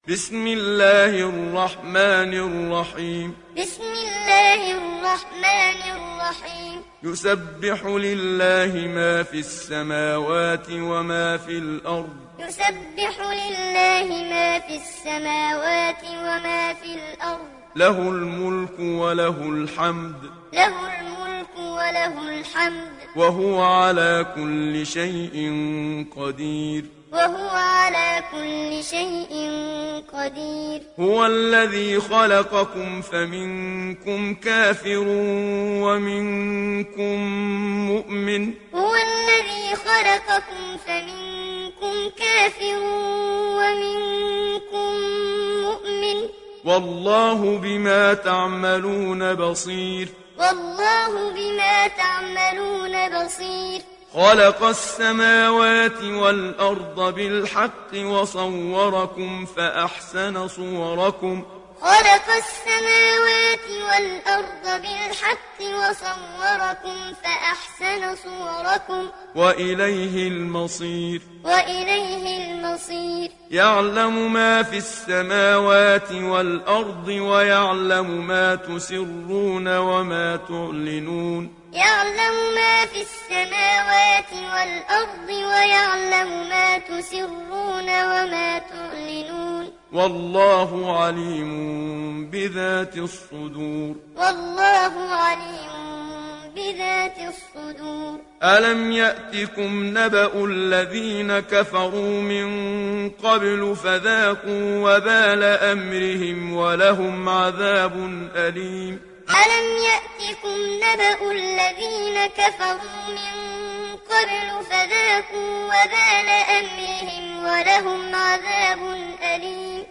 تحميل سورة التغابن mp3 بصوت محمد صديق المنشاوي معلم برواية حفص عن عاصم, تحميل استماع القرآن الكريم على الجوال mp3 كاملا بروابط مباشرة وسريعة
تحميل سورة التغابن محمد صديق المنشاوي معلم